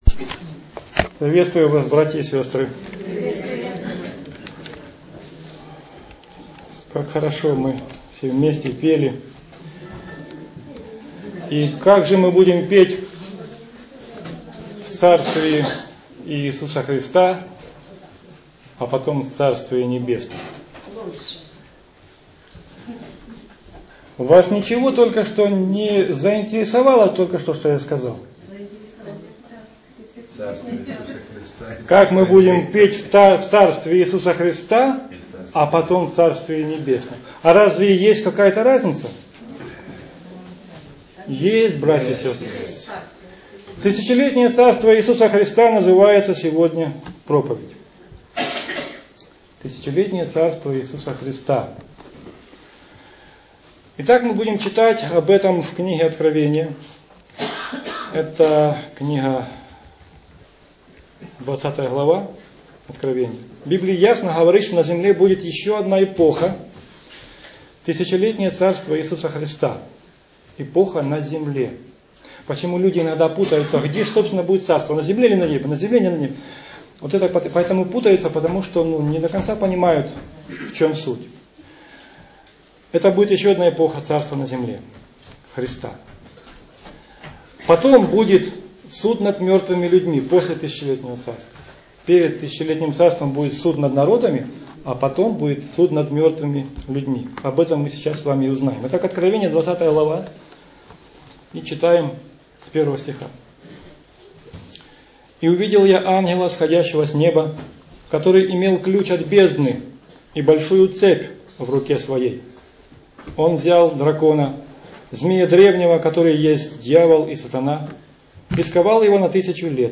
Аудио-проповедь Части 1 и 2 http